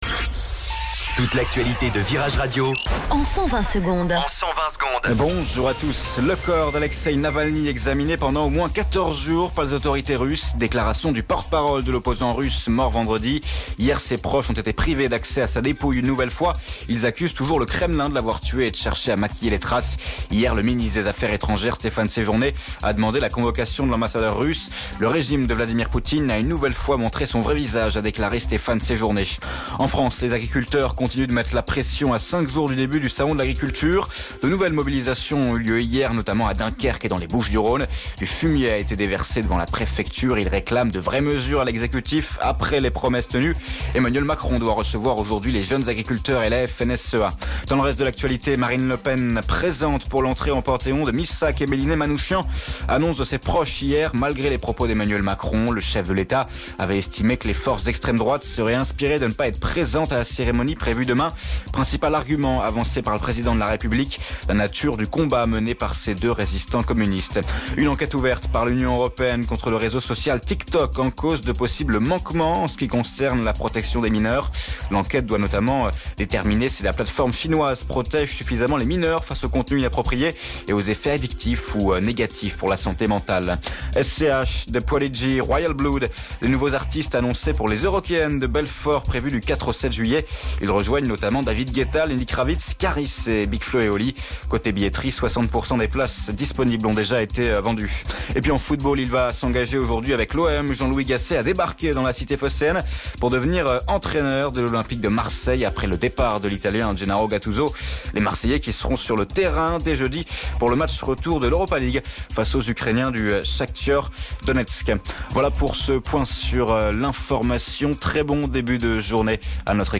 Flash Info Lyon